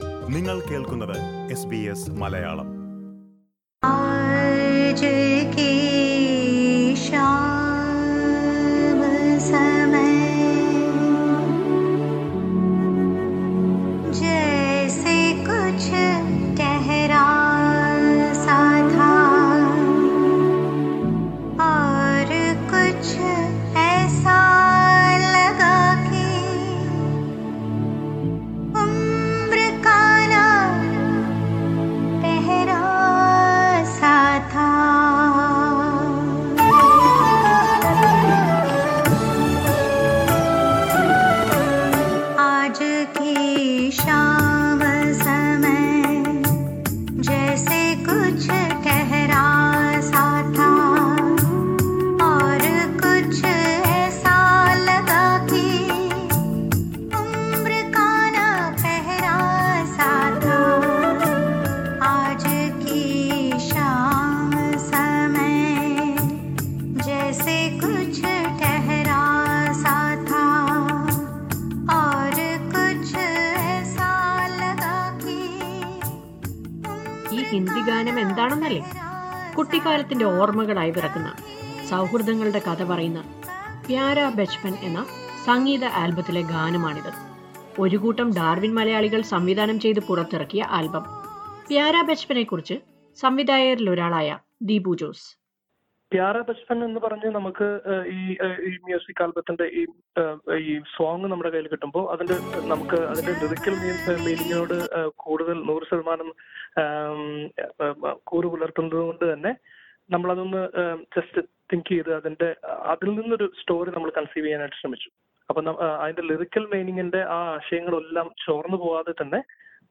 Listen to a report on Pyara Bachpan- Lovely Childhood, a Hindi music album acted by Darwin Malayalees and shot in Darwin.